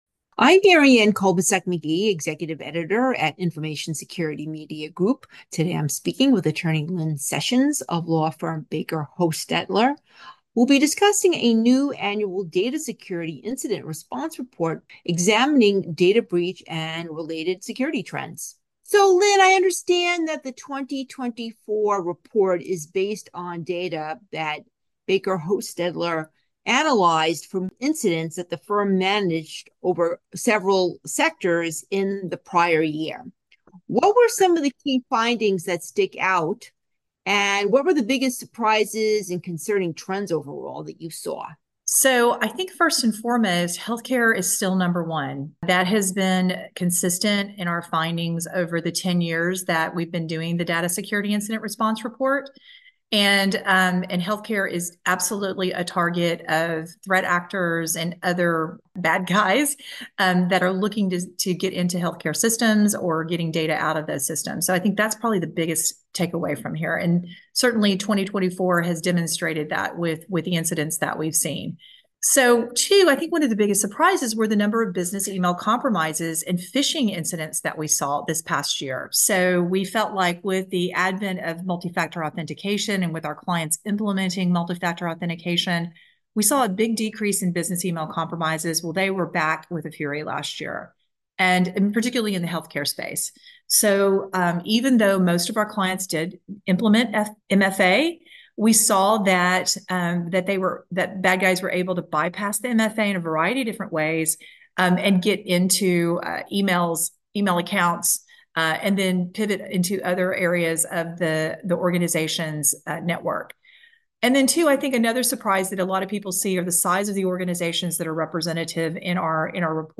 Exclusive, insightful audio interviews by our staff with data breach/security leading practitioners and thought-leaders